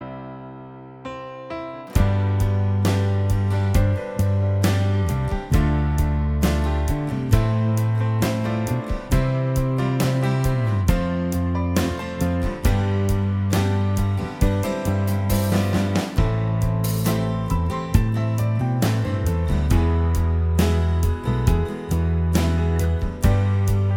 Minus Lead Guitar Rock 4:35 Buy £1.50